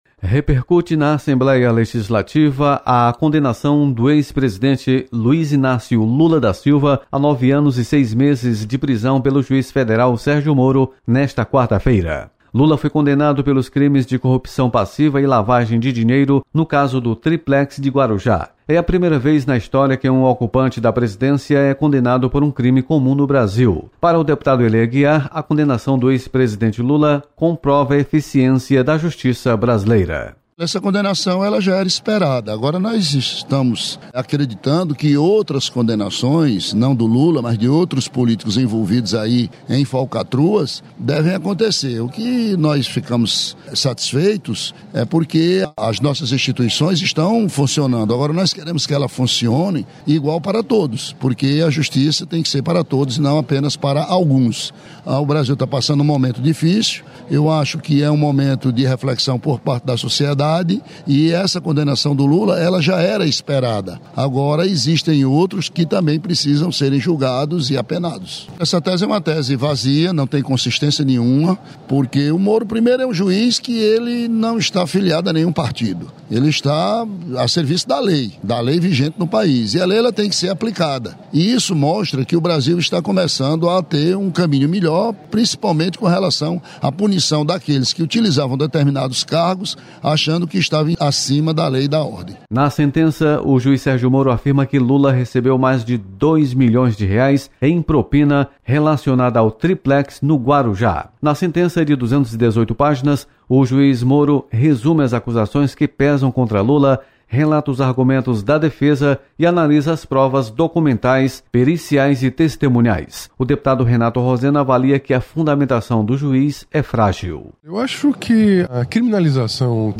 Parlamentares divergem sobre condenação do ex-presidente Lula. Repórter